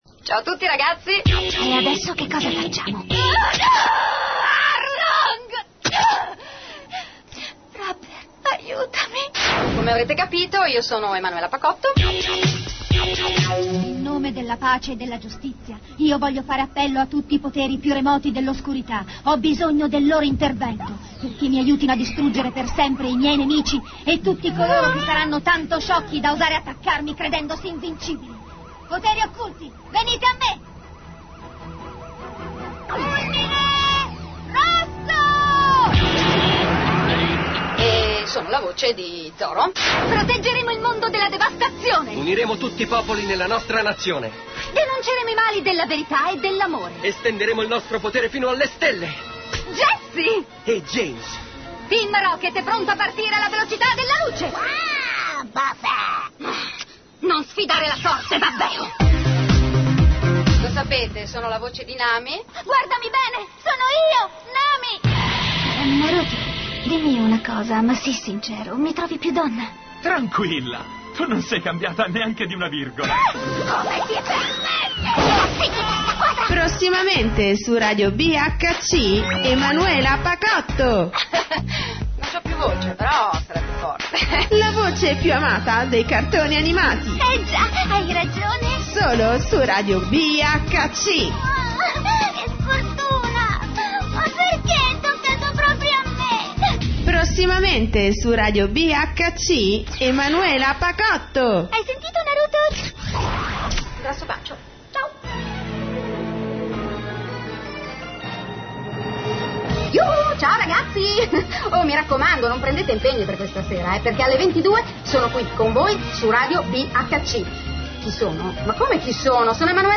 Per chi si fosse perso la diretta, ecco qui la mia divertente intervista radiofonica su RADIO BHC... E tra una chiacchera e l'altra, tante risate e anche qualche scoop!!!